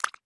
Звуки галочки
Плюх-плюх